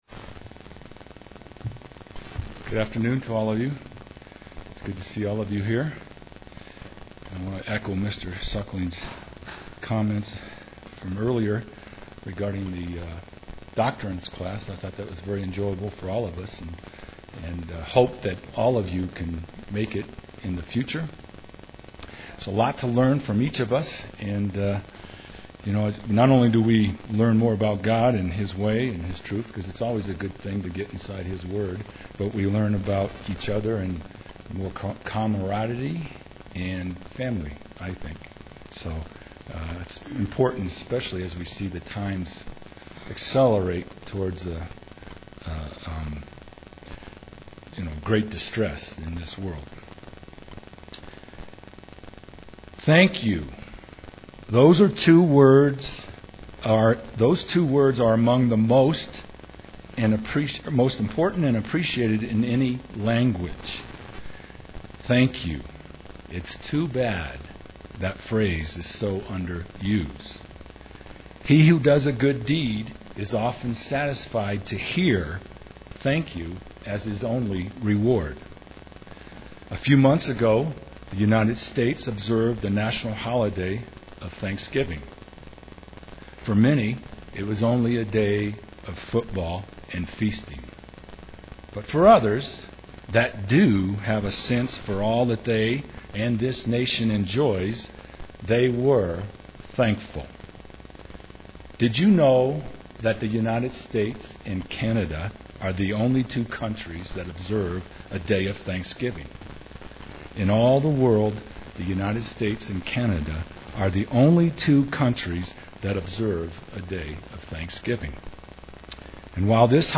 This sermon explores giving thanks from a Biblical perspective.
Given in Colorado Springs, CO